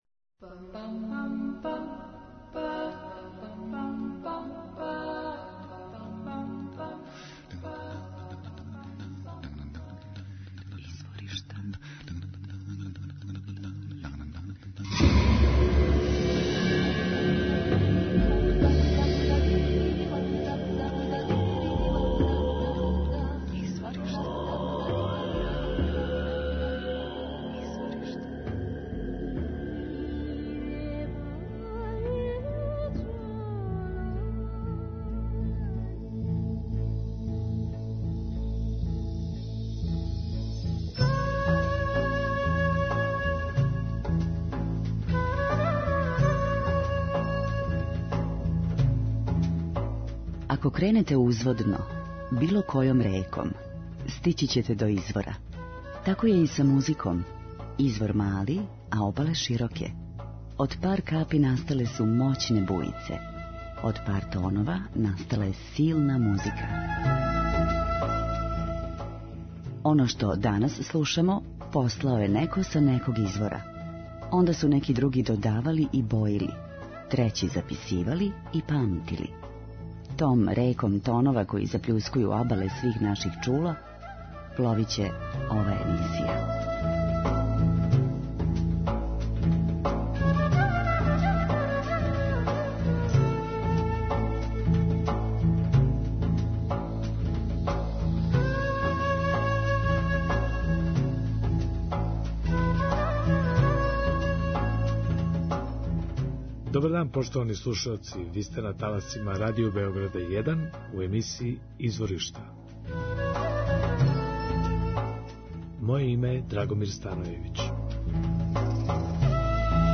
Фадо и фламенко - нове перспективе